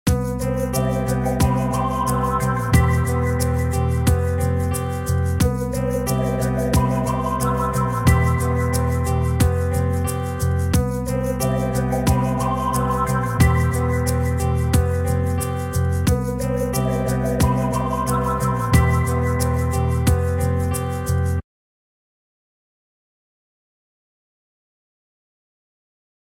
Nedan finns ljudfiler och tillhörande bilder för olika betoningar.
Trupp A Betoningar 1 (mp4)